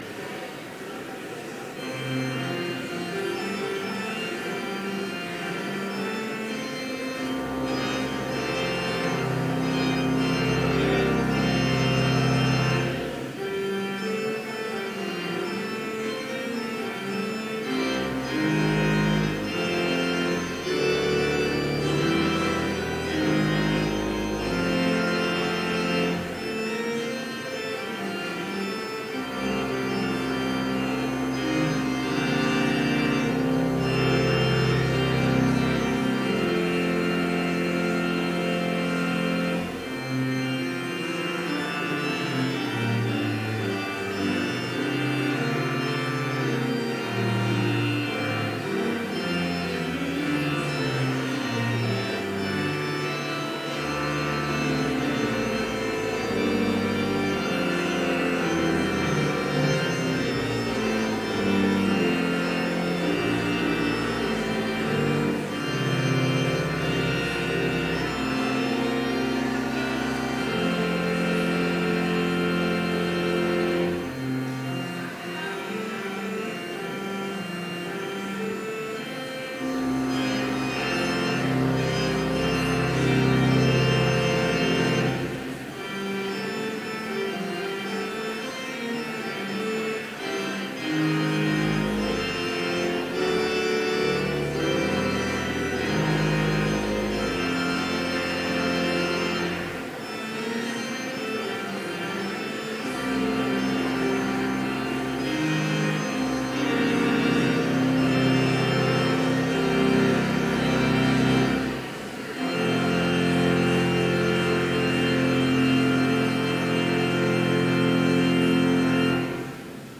Complete service audio for Chapel - August 23, 2016